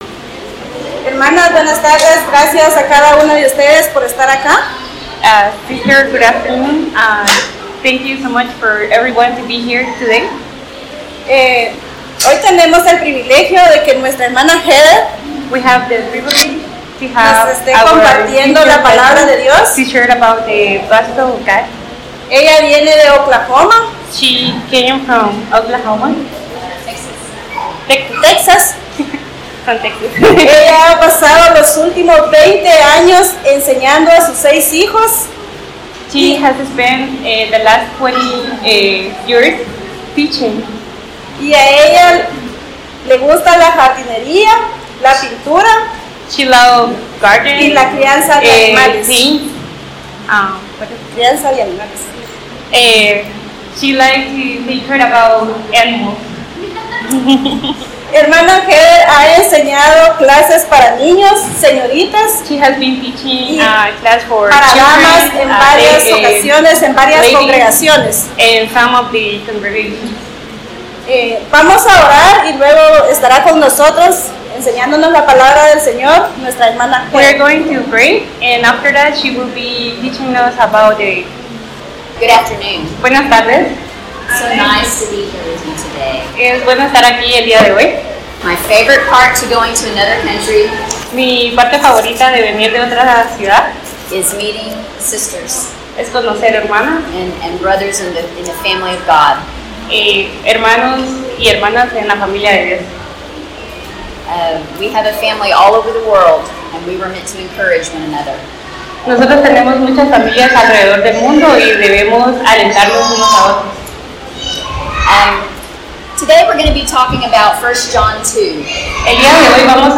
by admin | Apr 28, 2019 | ITL Lectureship 2019, Lectureships/Seminarios, Sermon